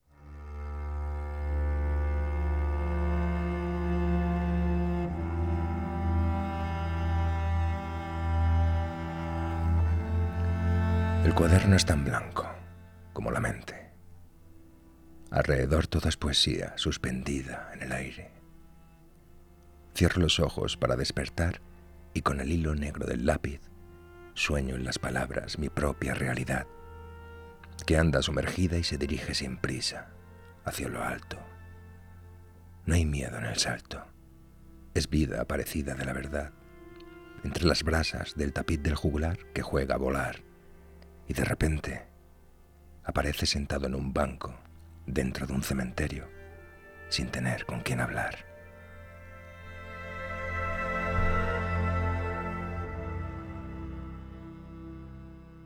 NO-HAY-MIEDO-AL-SALTO_POEMA-1202.mp3